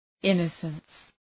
Προφορά
{‘ınəsəns}